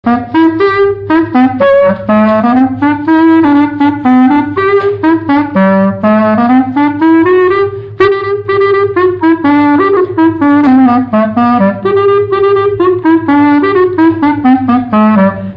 Résumé instrumental
danse : polka
Pièce musicale inédite